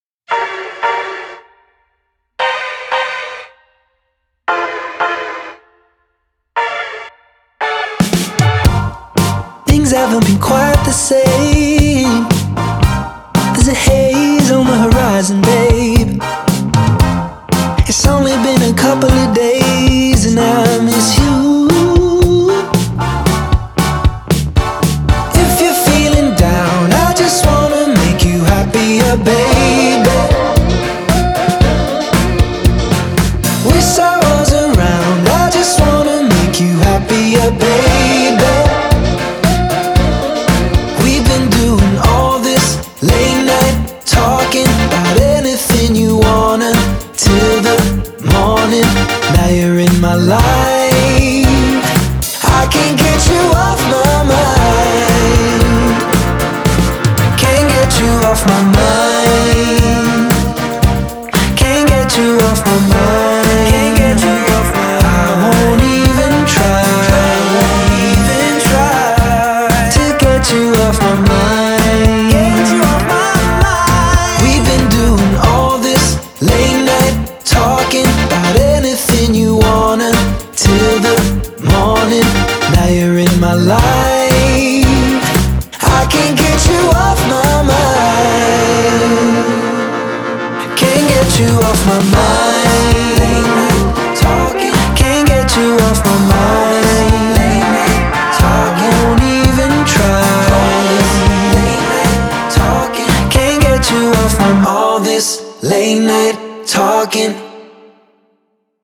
BPM115
Audio QualityPerfect (High Quality)